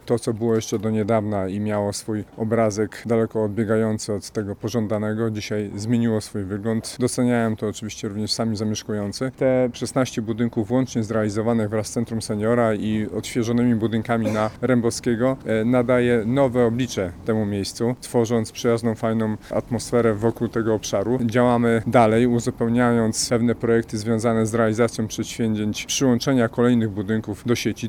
W kolejnych siedzibę będzie miało Zgierskie Centrum Seniora i instytucje kulturalne – mówi prezydent Zgierza, Przemysław Staniszewski.